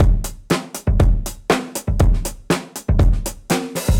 Index of /musicradar/dusty-funk-samples/Beats/120bpm